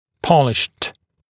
[‘pɔlɪʃt][‘полишт](от)полированный; блестящий, гладкий